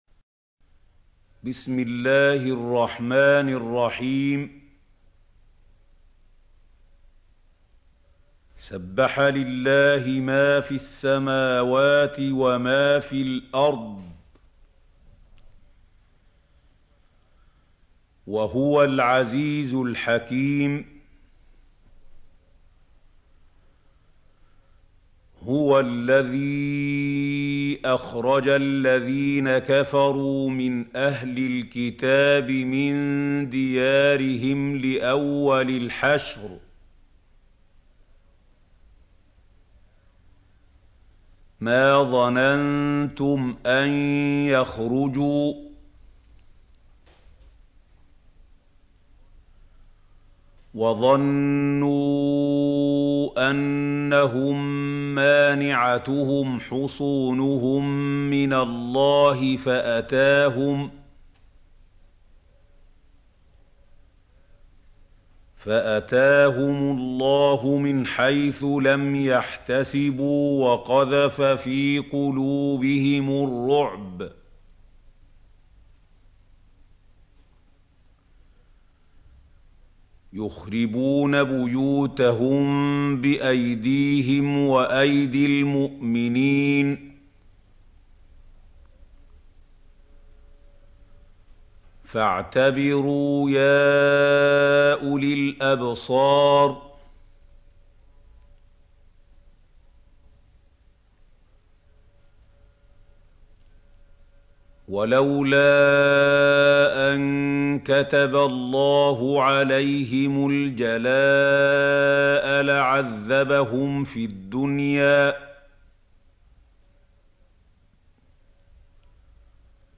سورة الحشر | القارئ محمود خليل الحصري - المصحف المعلم